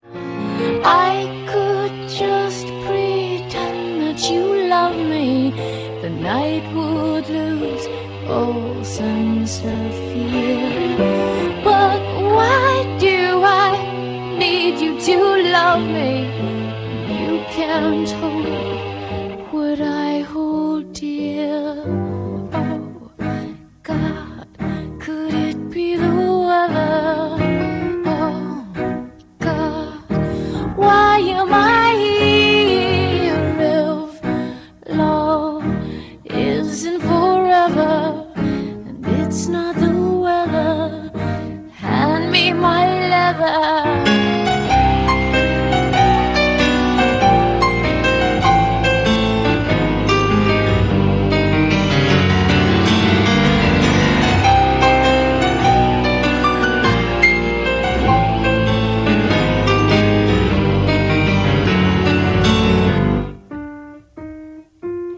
teasing, cabaret-like